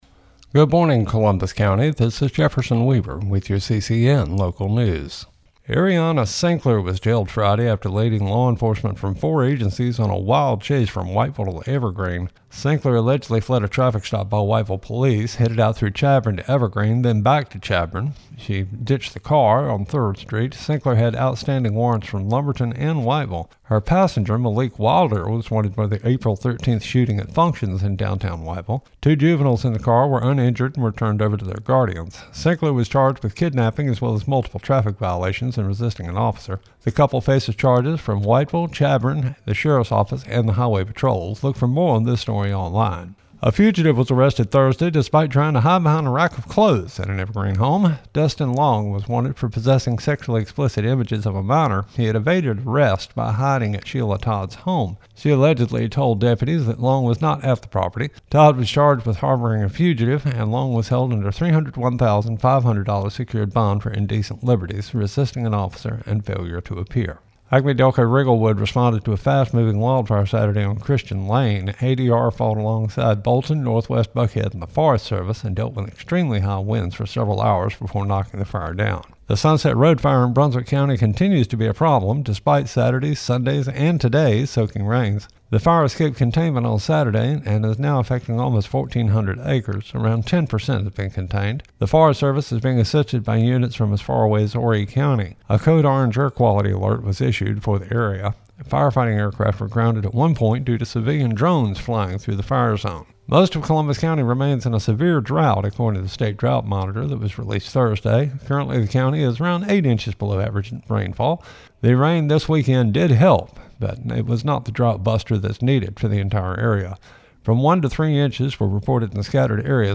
CCN Radio News — Morning Report for May 7, 2025
CCN-MORNING-NEWS-REPORT-2.mp3